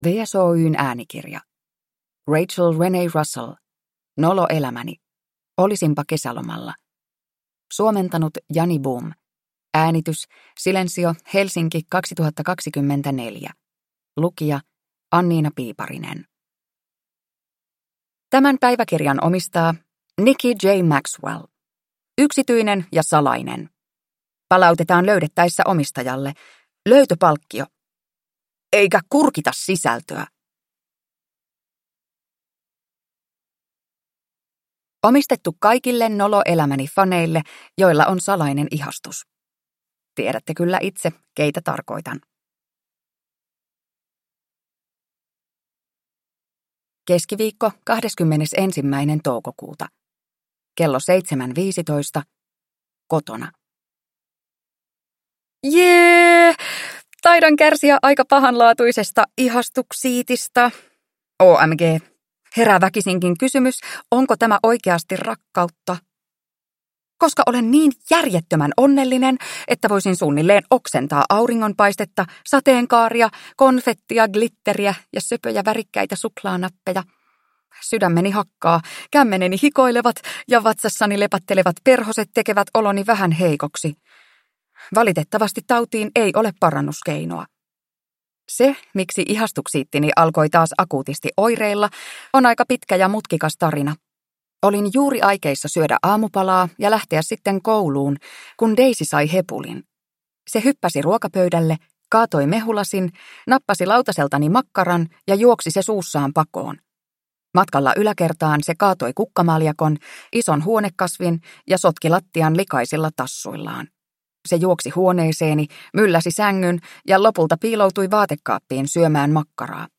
Nolo elämäni: Olisinpa kesälomalla – Ljudbok